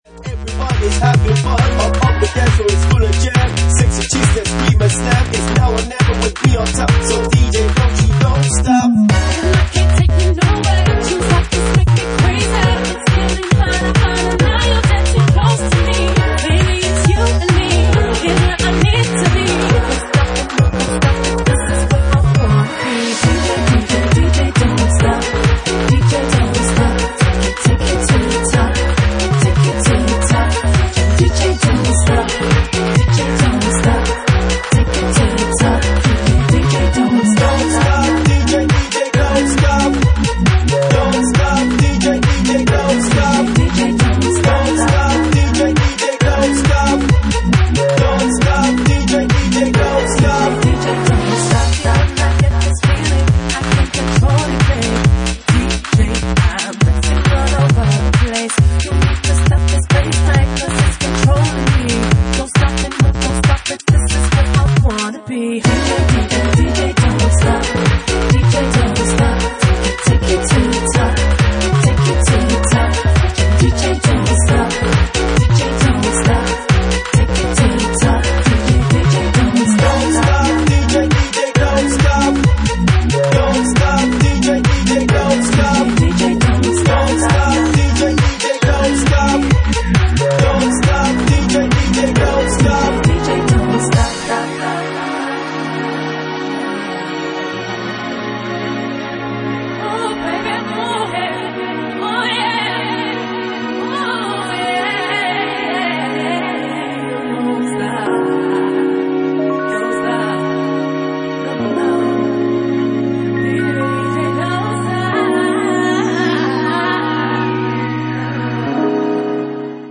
Genre:Bassline House
Bassline House at 136 bpm
Original Mix